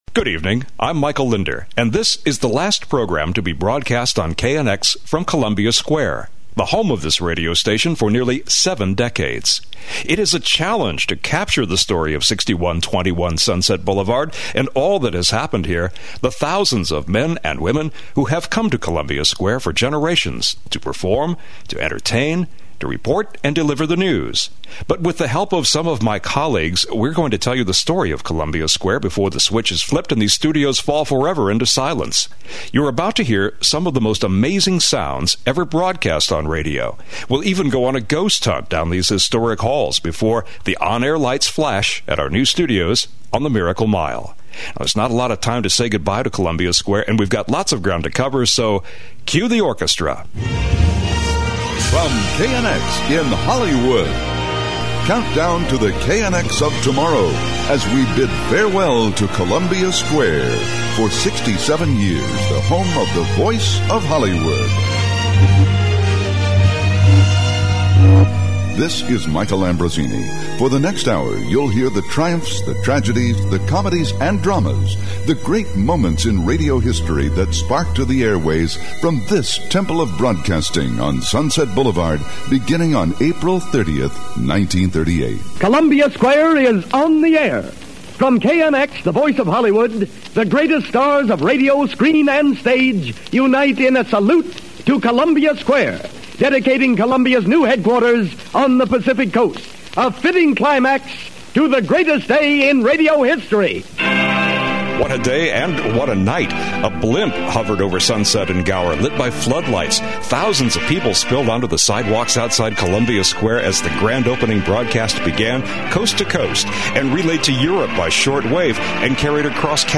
One of the audio clips heard comes from a live Steve Allen show, which aired nightly at midnight, in front of a live studio audience.
KNX-final-Columbia-Square-2005-compressed.mp3